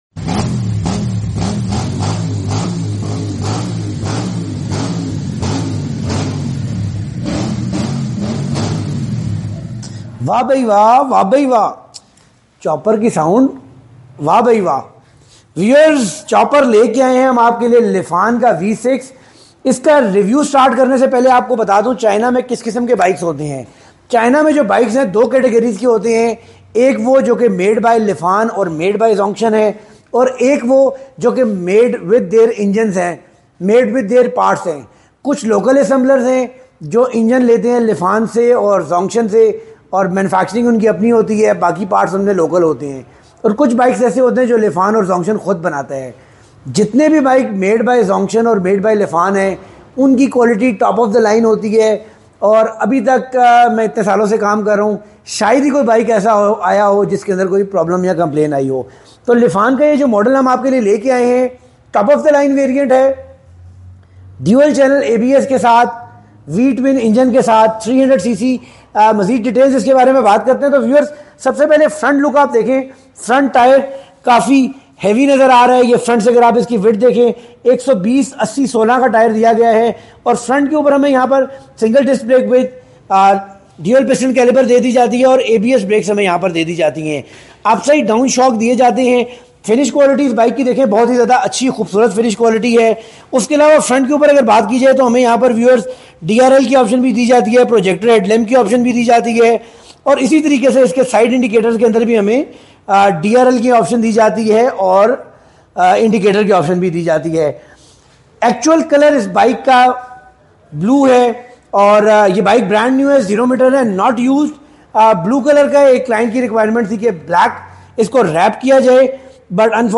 Wah Bai Wah Chopper Ka sound effects free download
Wah Bai Wah Lifan Made Chopper 300cc